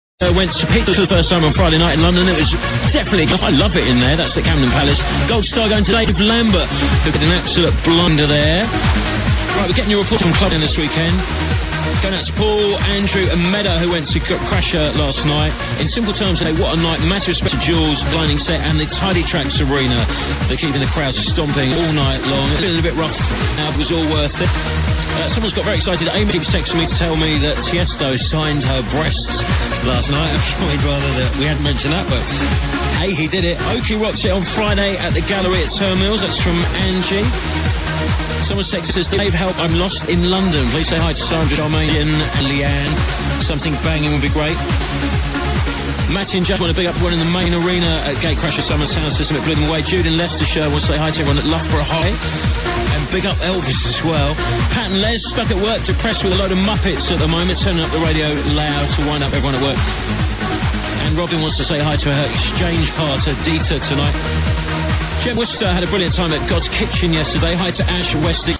Smoking ..umm..something really banging tune needs iding